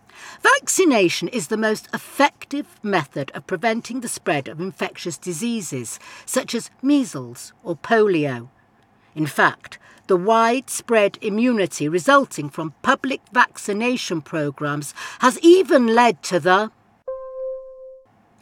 The recording ends with a tone before the last sentence is complete.
• בכל קטעי השמע תשמעו אדם אחד בלבד מדבר.